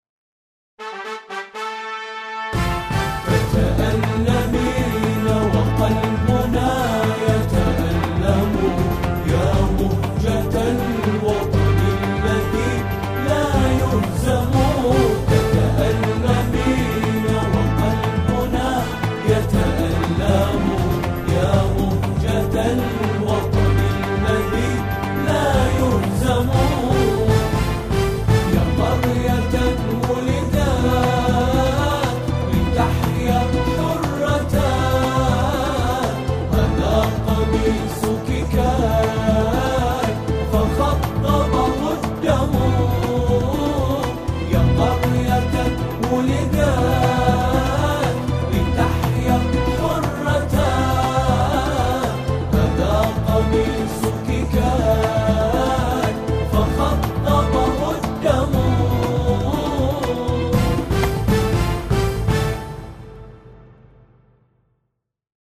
أناشيد بحرينية